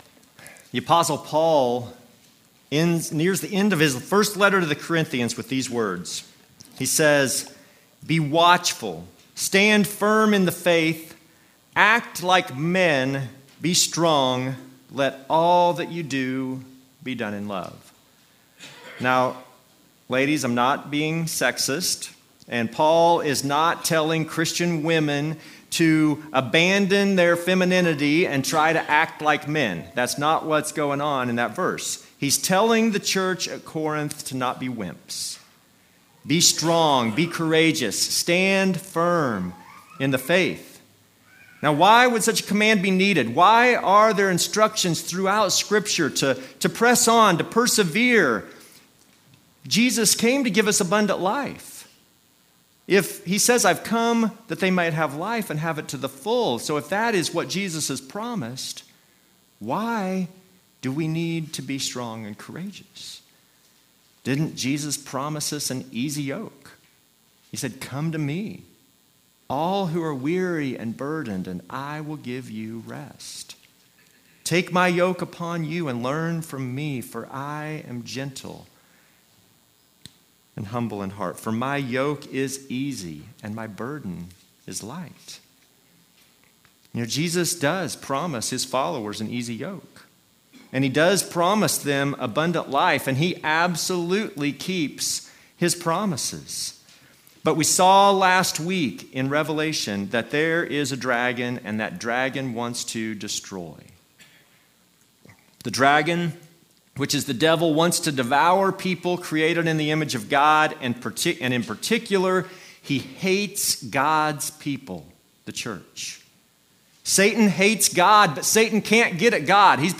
Passage: Revelation 13:1-10 Service Type: Normal service